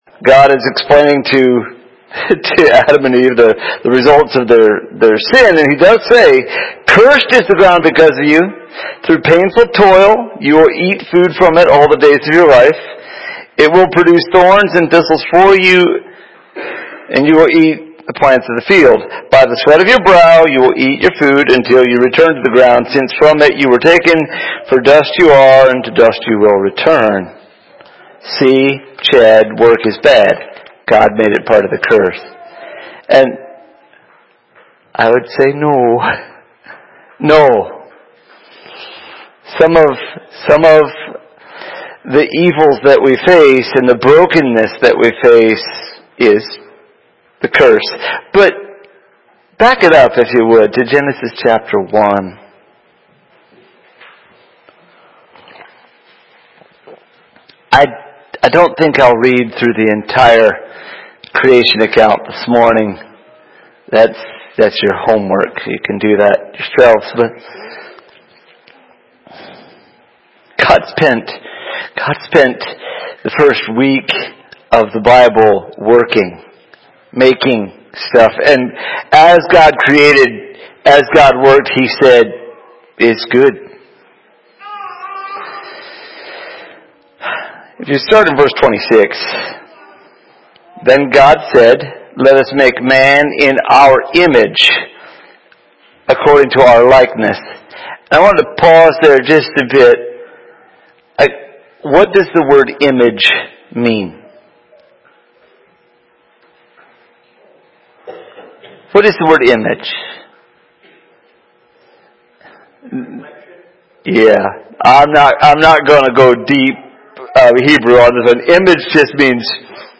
Sermon Shortened Due To Software Glitch.